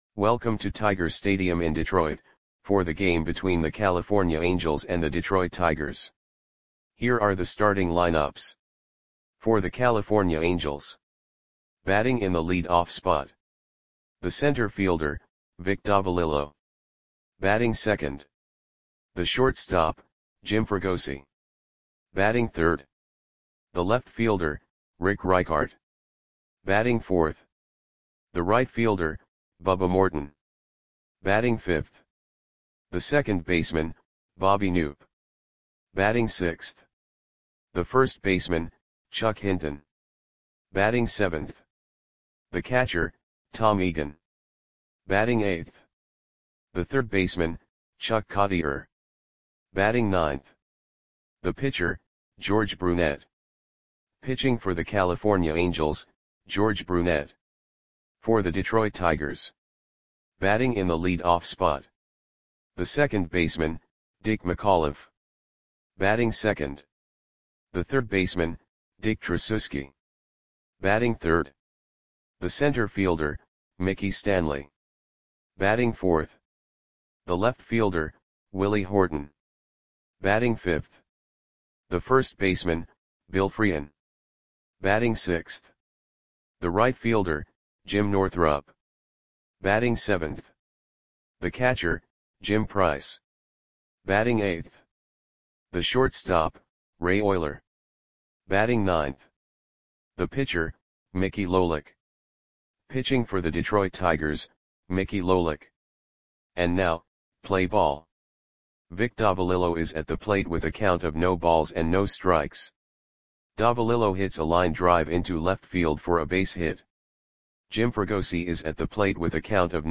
Lineups for the Detroit Tigers versus California Angels baseball game on August 29, 1968 at Tiger Stadium (Detroit, MI).
Click the button below to listen to the audio play-by-play.